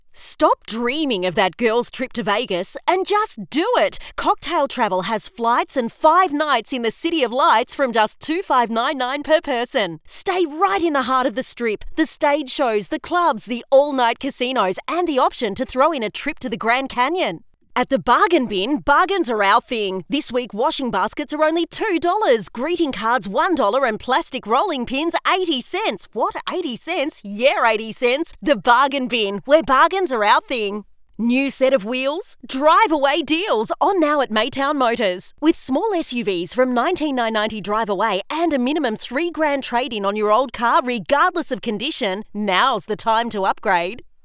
• Hard Sell
• Emotive
• Bright
• Mic: Rode NT1-A